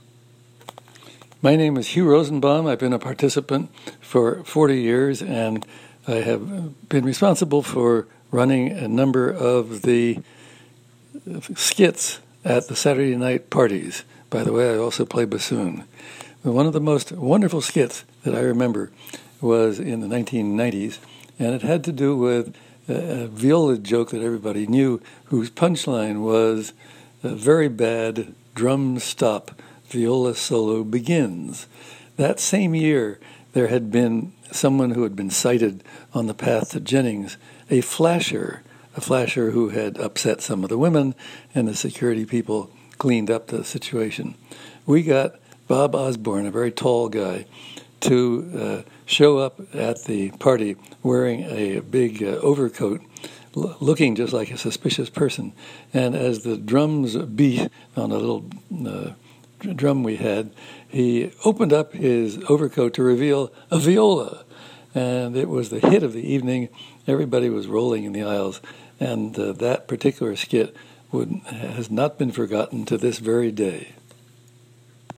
CMC Stories was initiative to collect oral histories from the CMC community.